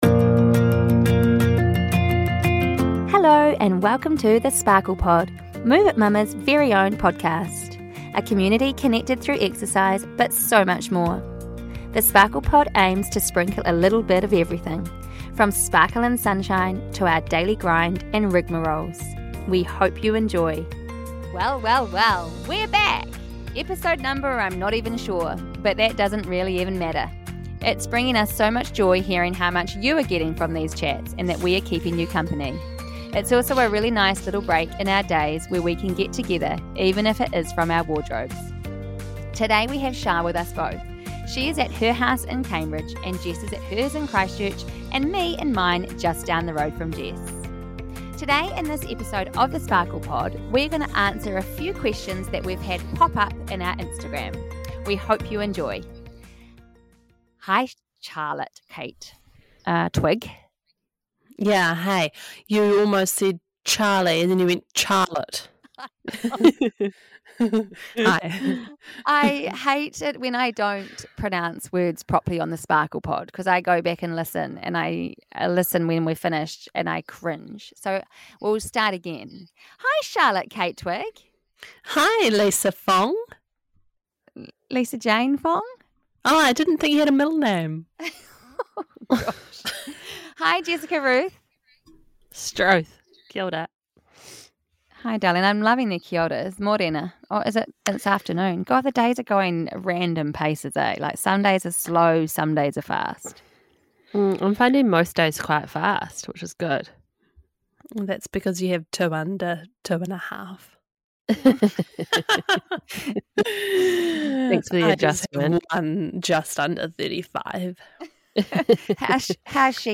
For a bit of light-hearted chatter, the three of us answer some semi quick-fire questions to round out the year (cue, waffle city).